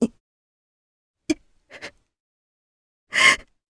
Hilda-Vox_Sad_jp.wav